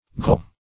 Ghoul \Ghoul\ (g[=oo]l), n. [Per. gh[=o]l an imaginary sylvan